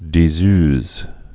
(dē-zœz, də-)